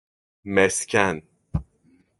Pronunciado como (IPA) /mesˈcen/